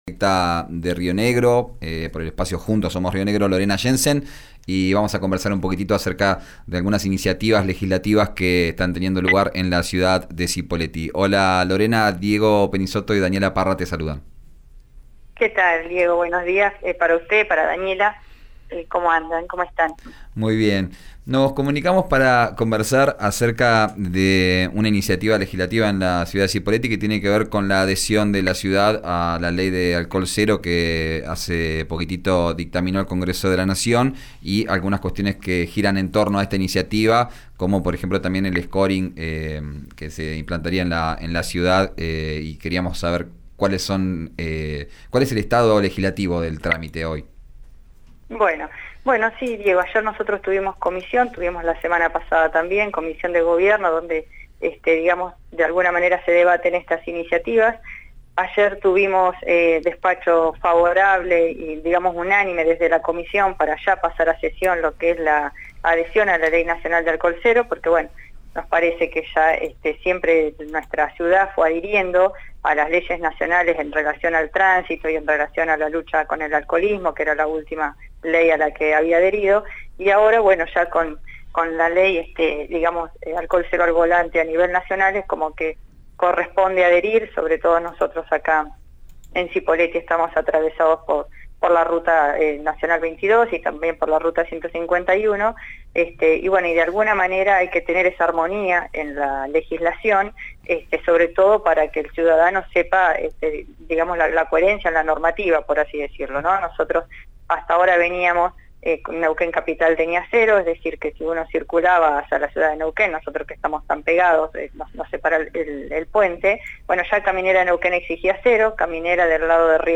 La concejal Lorena Yensen expresó en “Vos al aire” por RÍO NEGRO RADIO que vienen trabajando hace varias semanas el proyecto, y que finalmente ayer obtuvo despacho favorable.